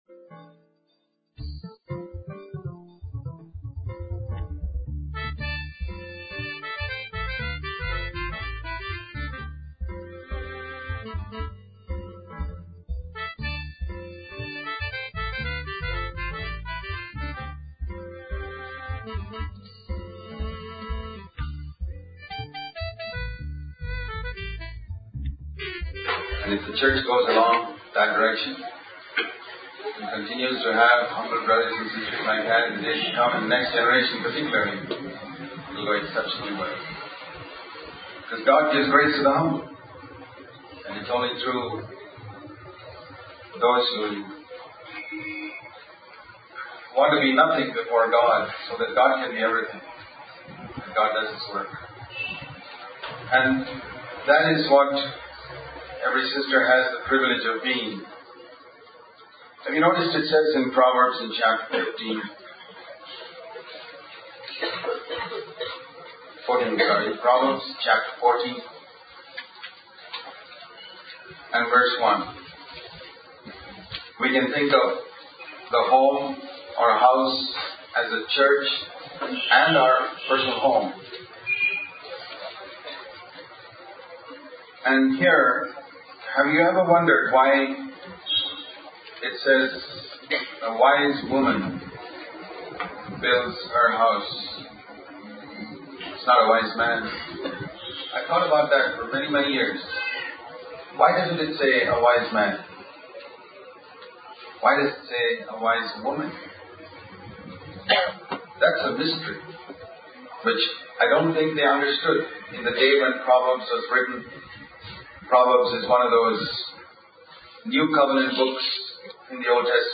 In this sermon, the speaker emphasizes the importance of understanding the first part of Hebrews 4:15, which states that Jesus can sympathize with our needs and struggles.